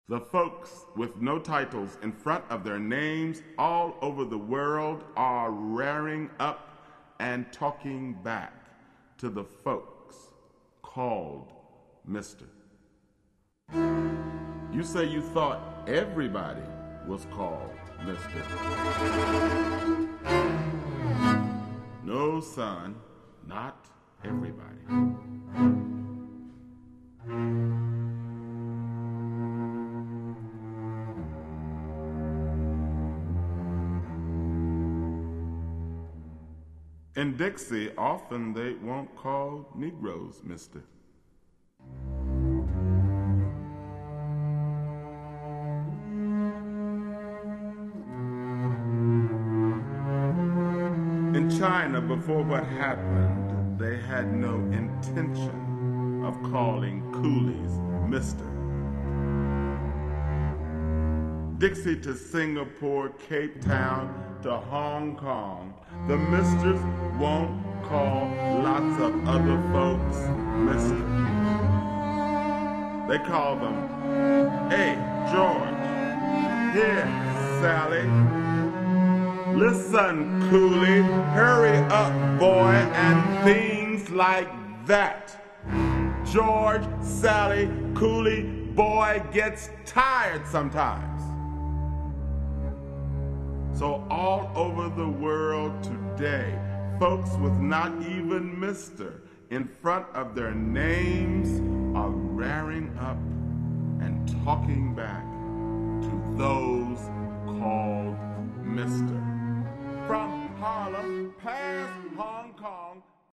Please note: These samples are not of CD quality.